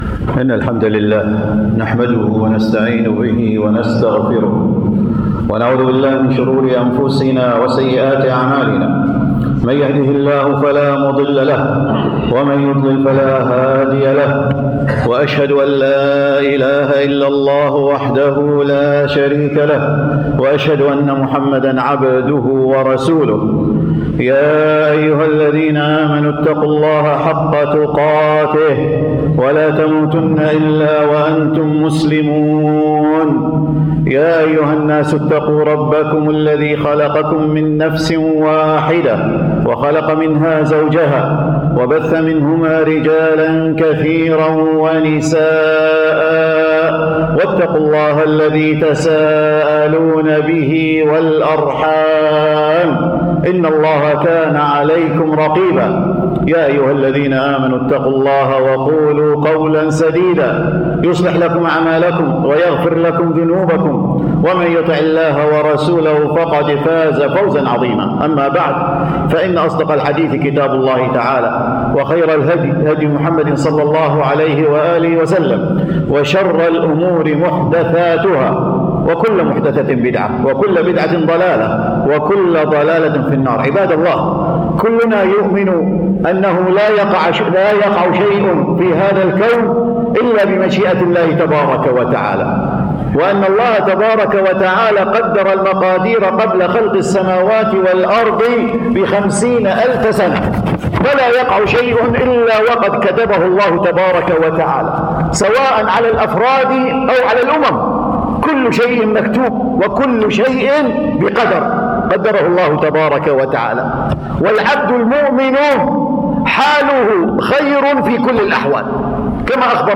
خطبة الجمعة بتاريخ 3 أبريل